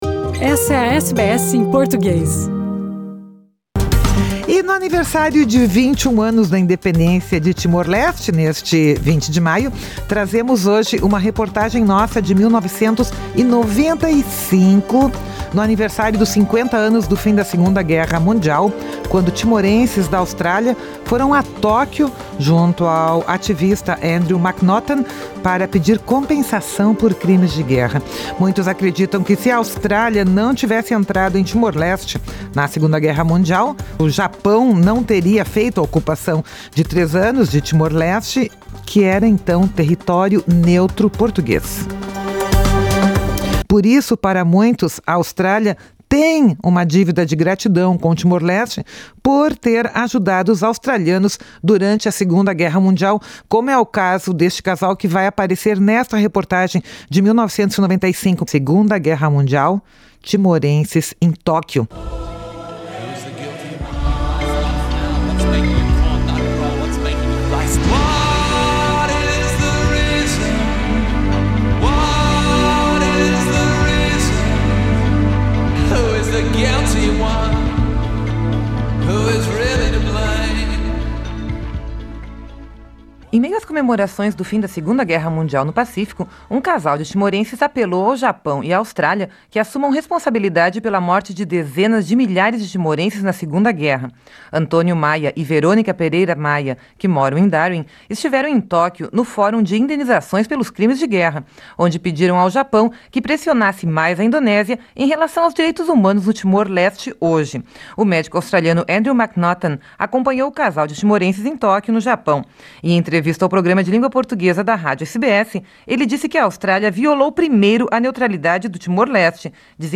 ***Reportagem de arquivo, que foi originalmente ao ar em 1995.